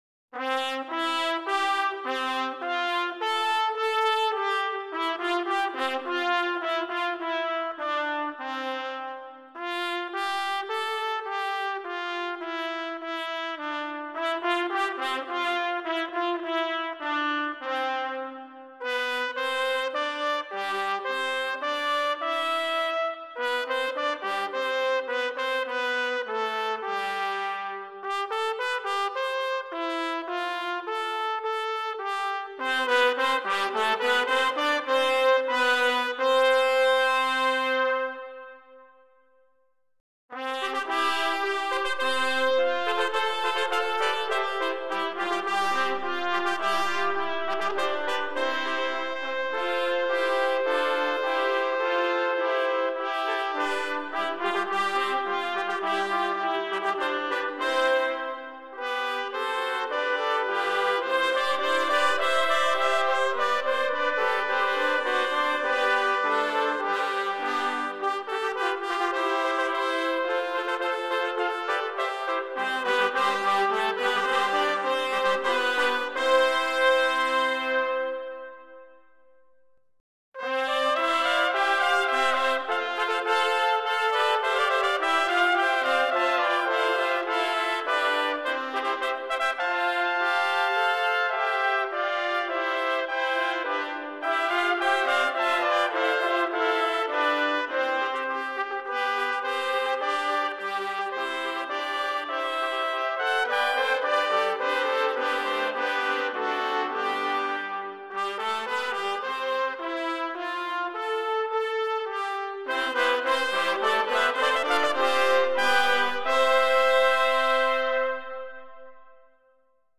Number of Trumpets: 3
Exhilarating, majestic, and inspiring.